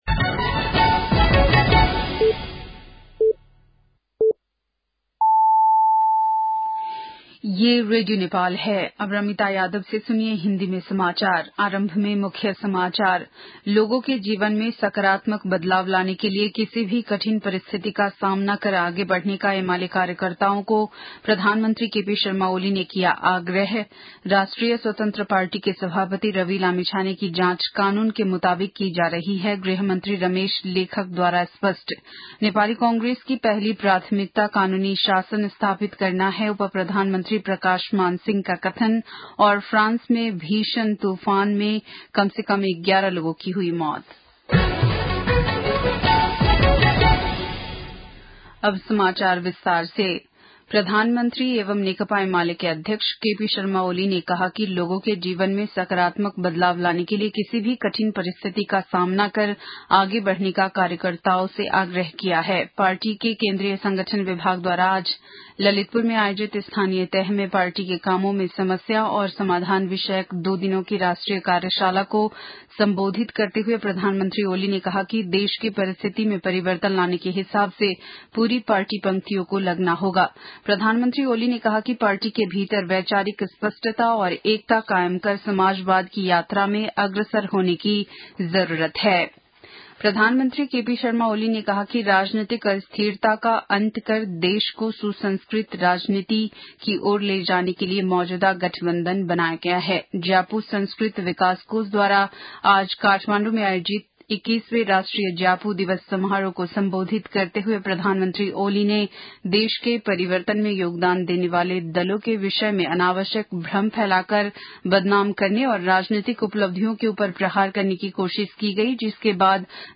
10-pm-hindi-news-8-30.mp3